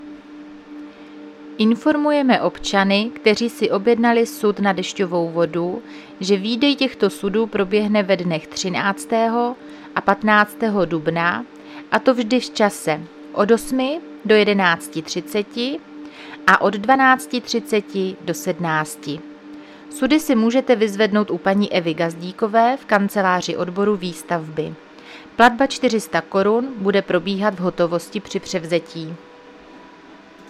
Záznam hlášení místního rozhlasu 10.4.2026